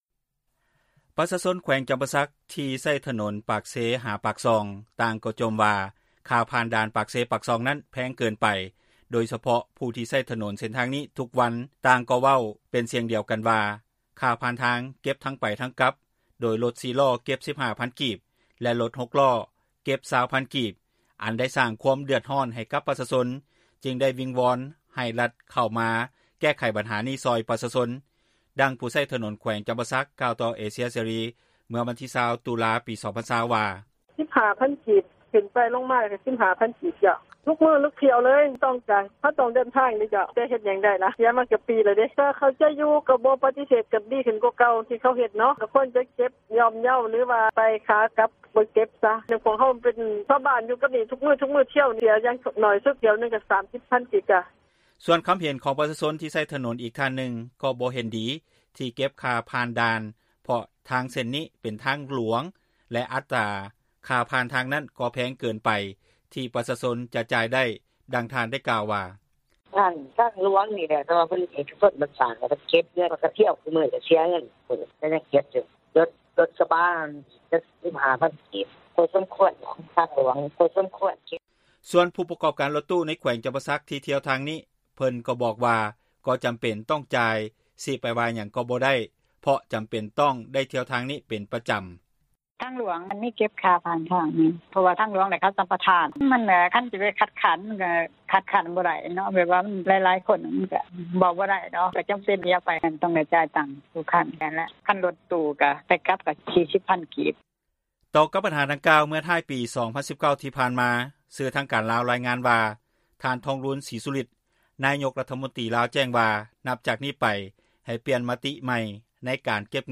ດັ່ງຜູ້ໃຊ້ຖນົນຢູ່ແຂວງຈຳປາສັກ ກ່າວຕໍ່ເອເຊັຽ ເສຣີ ເມື່ອວັນທີ 20 ຕຸລາ 20 ວ່າ: